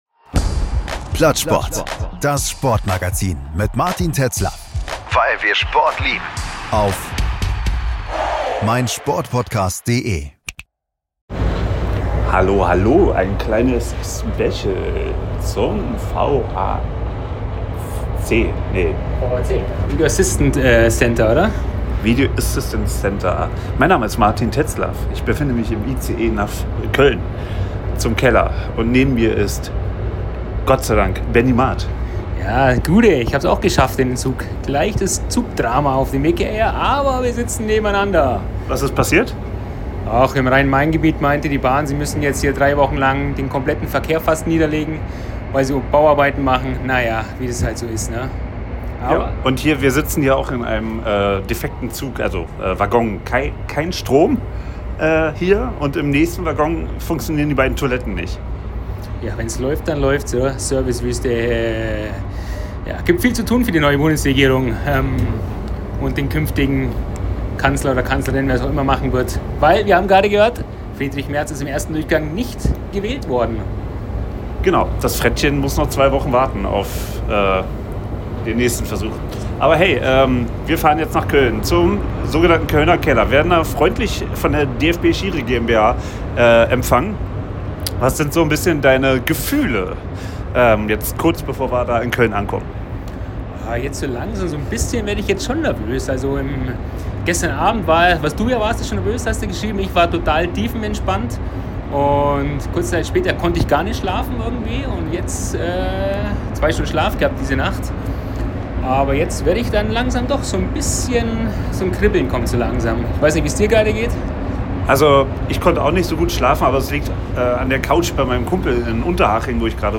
Wir haben im Zug, quasi vor dem Kölner Dom und auf dem Umstieg zurück nach Hause am Airport in Frankfurt unsere Erwartungen gebündelt und sprechen im Nachgang sehr begeistert und dennoch reflektiert über unsere Eindrücke.